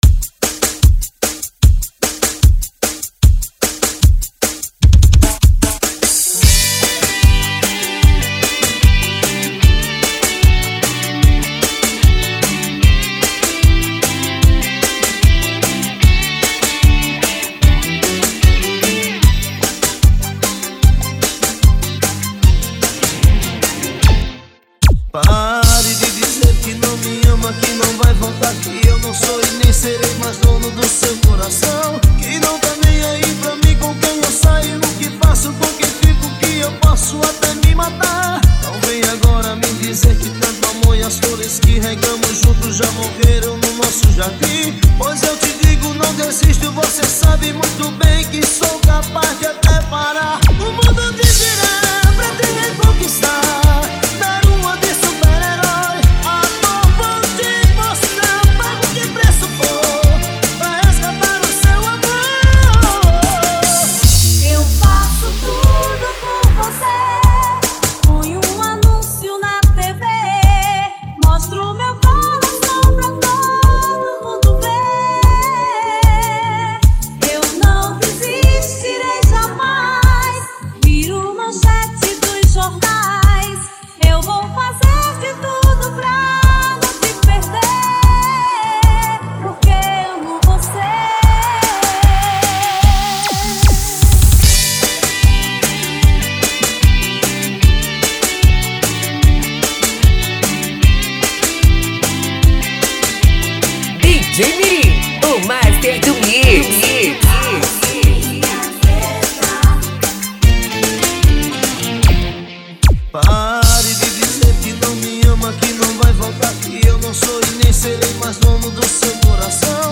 Melody